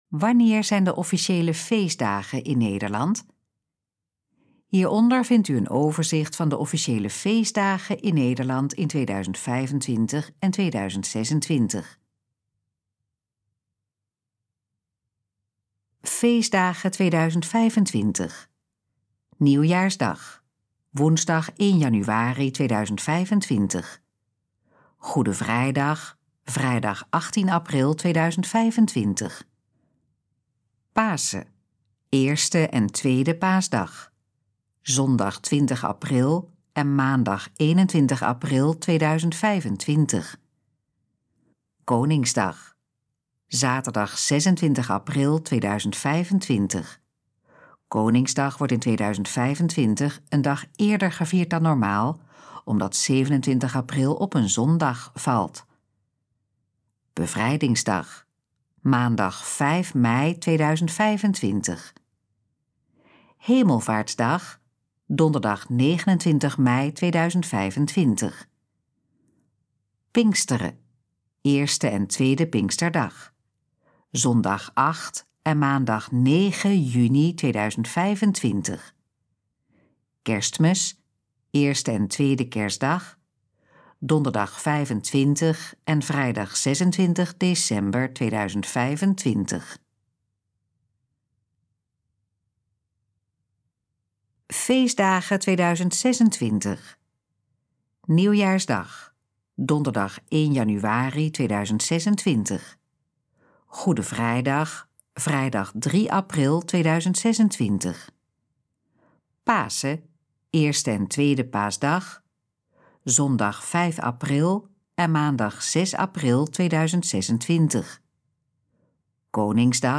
Gesproken versie van: Wanneer zijn de officiële feestdagen in Nederland?
Dit geluidsfragment is de gesproken versie van de pagina: Wanneer zijn de officiële feestdagen in Nederland?